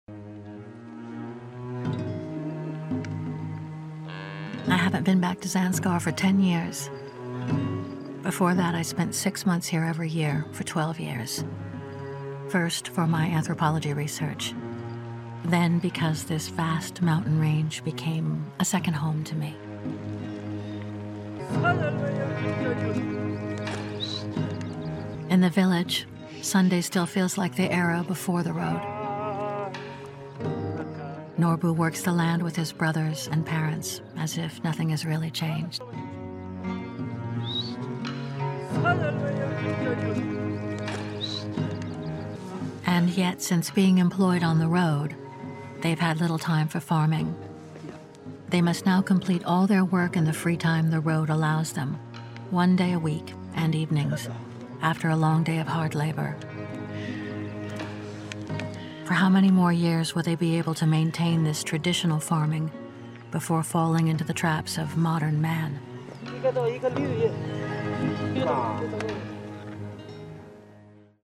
Documentary The Road de Marianne Chaud